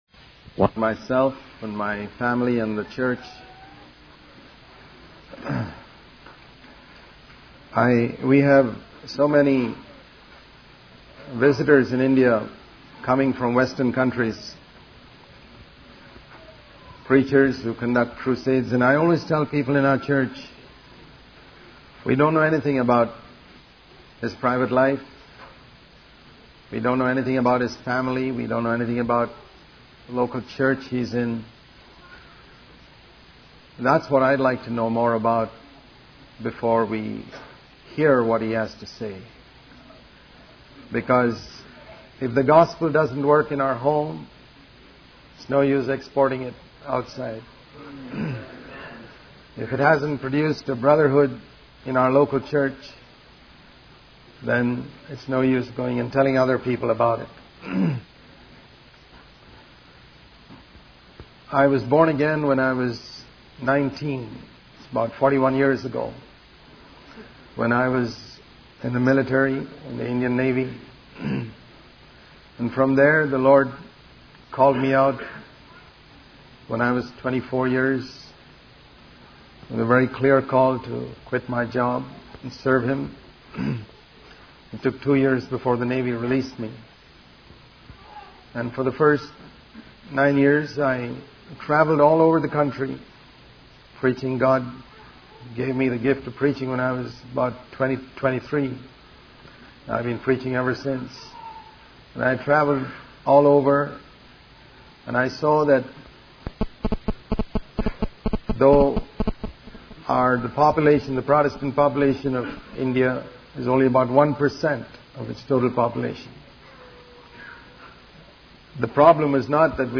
In this sermon, the speaker discusses the current state of religious fundamentalism and persecution in the country.